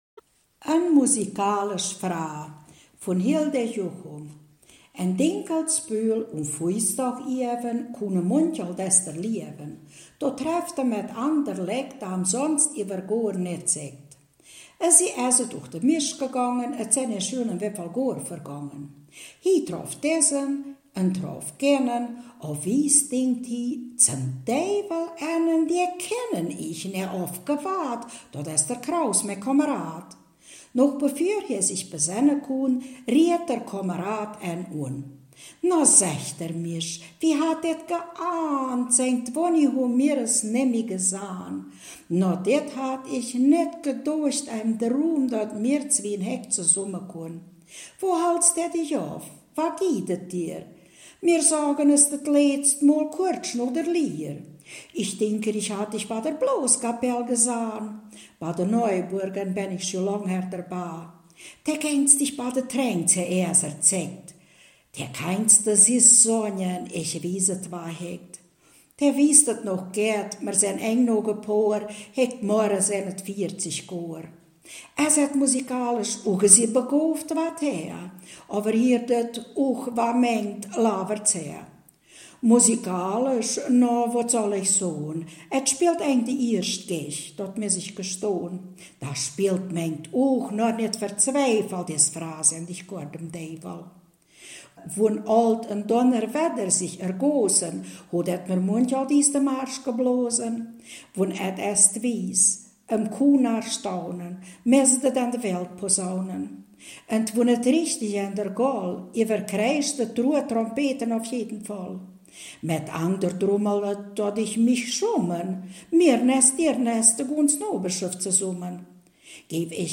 Ortsmundart: Frauendorf